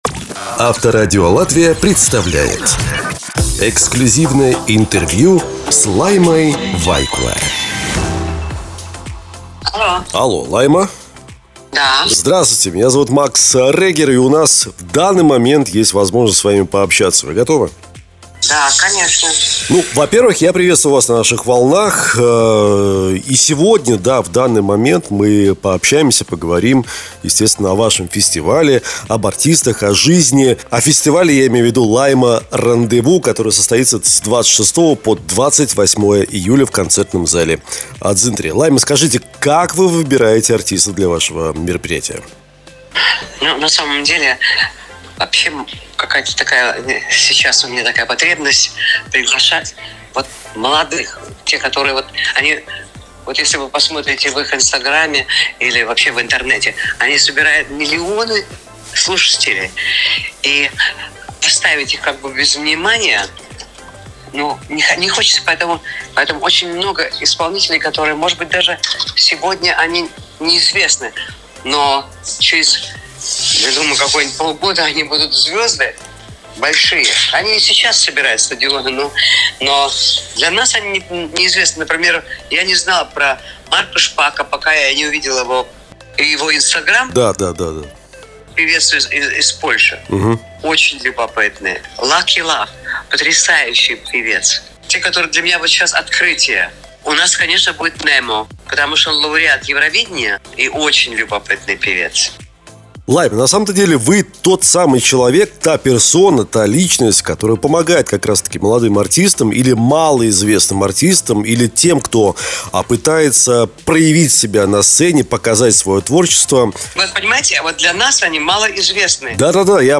Интервью с Лаймой Вайкуле